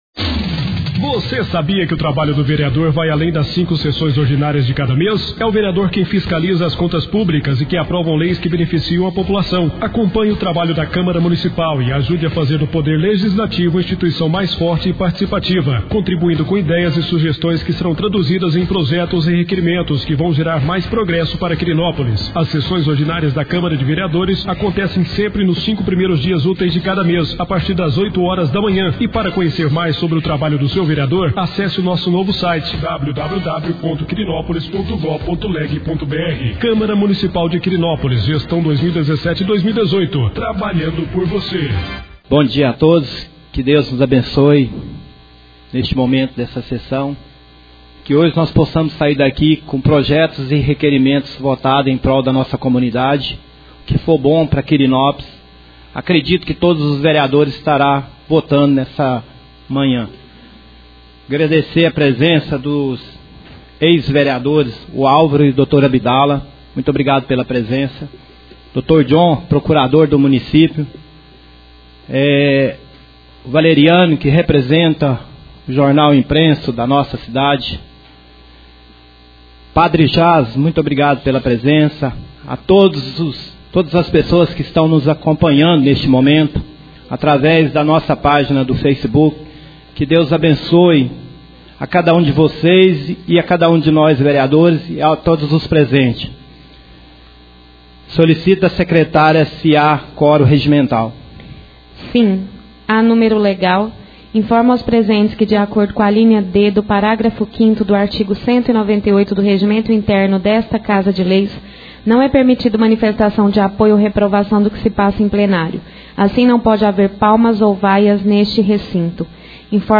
5° Sessão Ordinária do Mês de Junho 2017.
Áudios das Reuniões